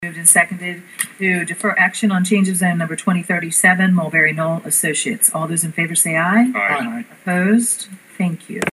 The Sussex County Planning and Zoning Commission held a lengthy public hearing with packed Council Chambers late yesterday afternoon for a Change of Zone request, #2037, from Mulberry Knoll Associates, LLC.